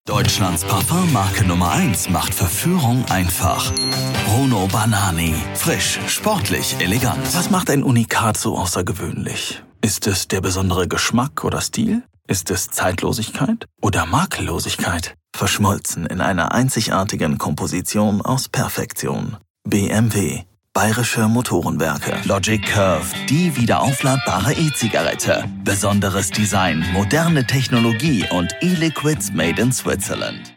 Extremely versatile voice perfect for animations and commercials.
Neumann TLM 193 condenser mic SPL Channel One, tube preamp and equaliser
Green Acoustics treatment